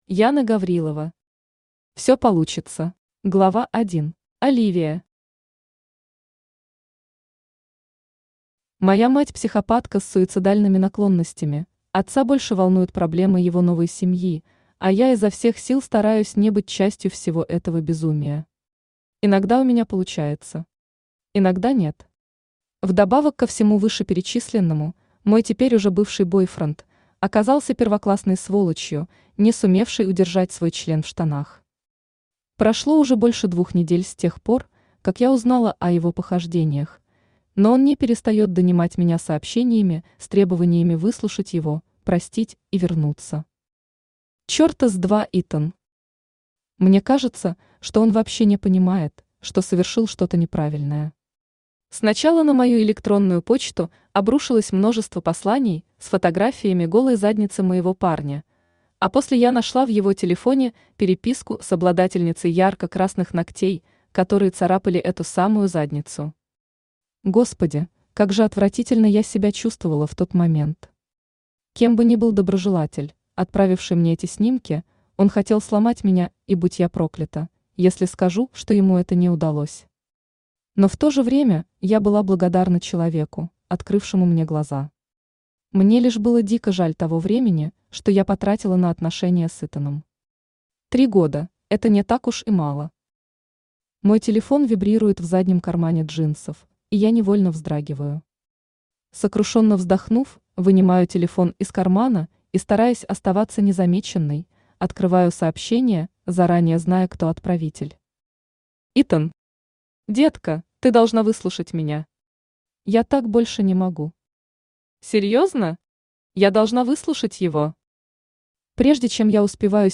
Aудиокнига Всё получится Автор Яна Гаврилова Читает аудиокнигу Авточтец ЛитРес.